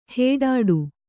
♪ heḍḍu